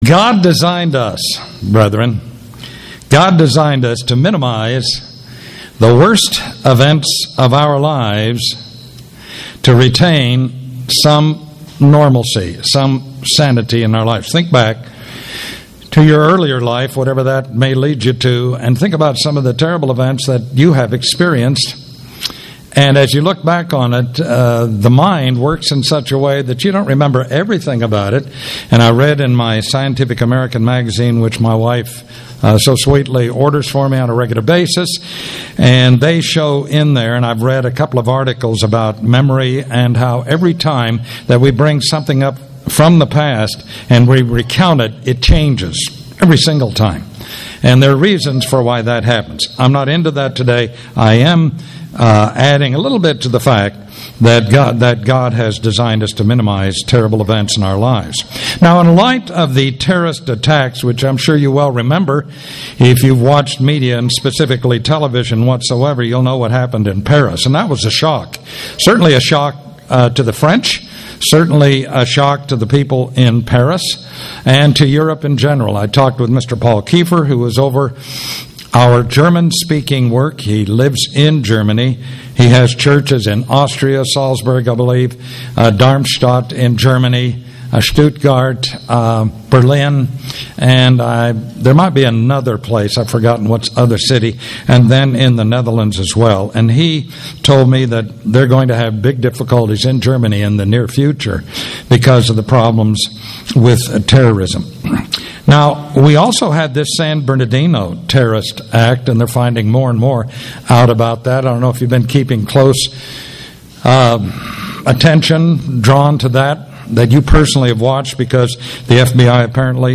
Given in Atlanta, GA Buford, GA